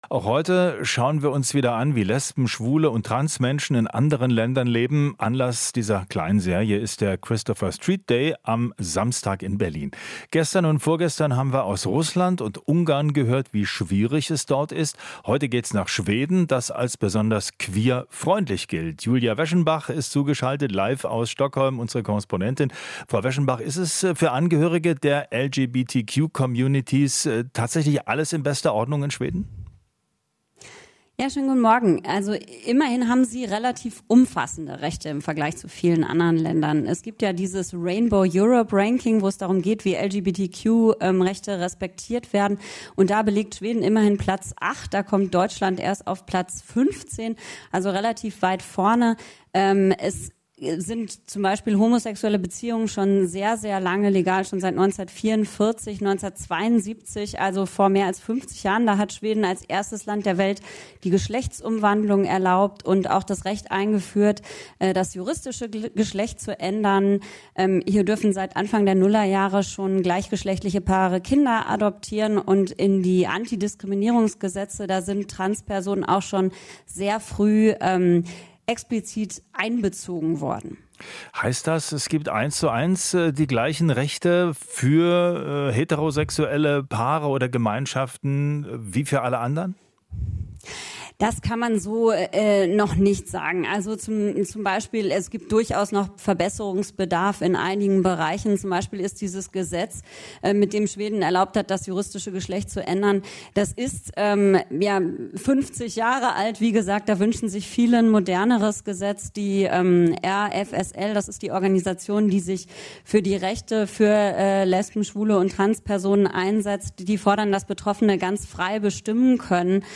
Interview - Umfassende Rechte für queere Menschen in Schweden